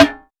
SNARE.93.NEPT.wav